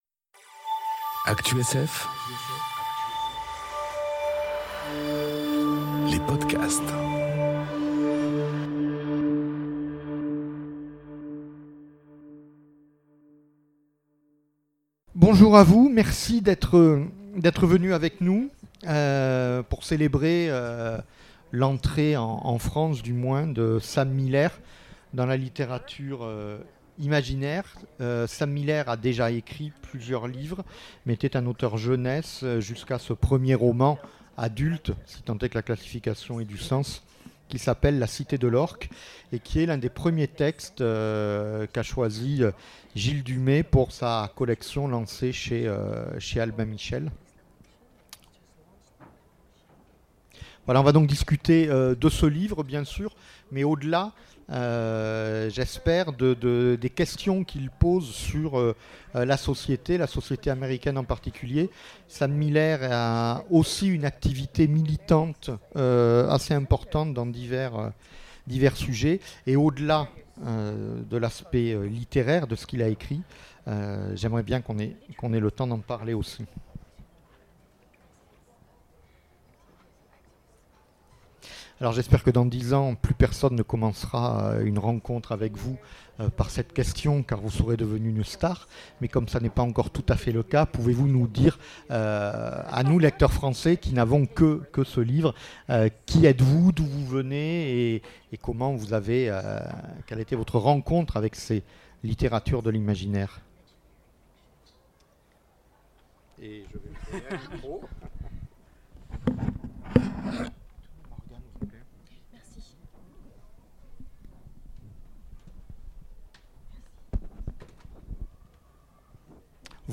Imaginales 2019 : Le Grand entretien avec Sam J. Miller